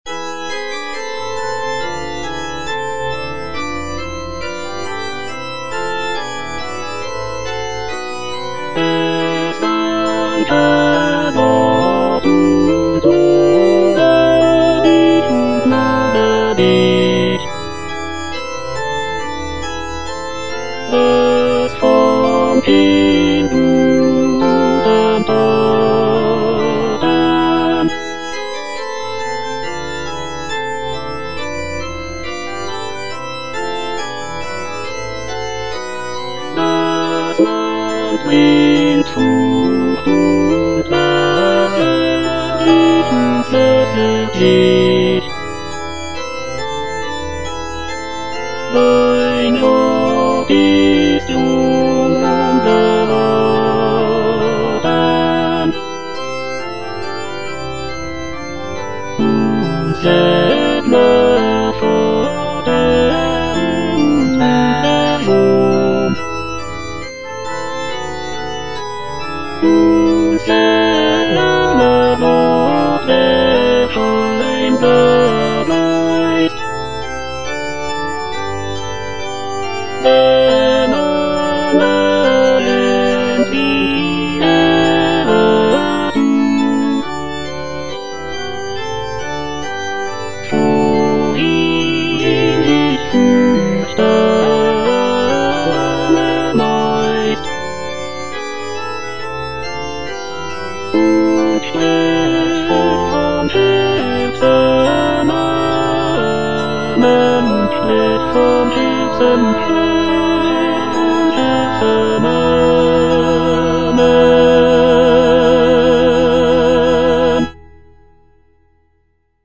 Cantata
Tenor (Emphasised voice and other voices) Ads stop